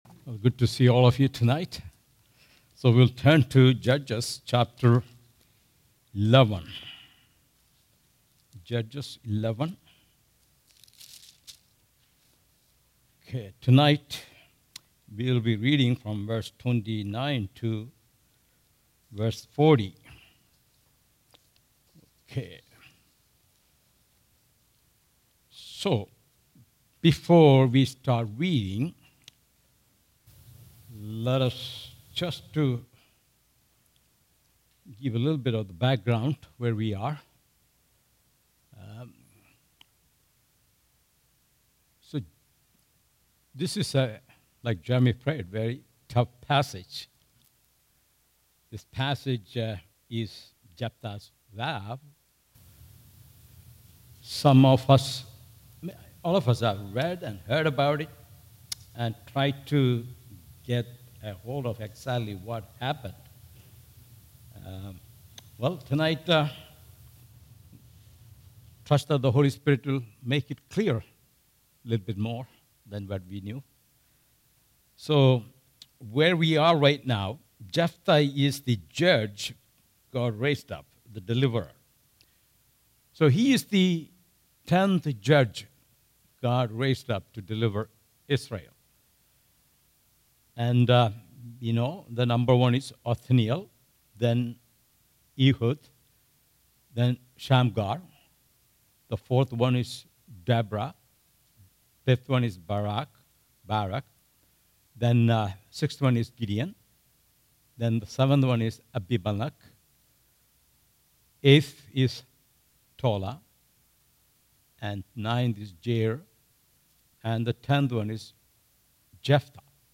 All Sermons Judges 11:29-40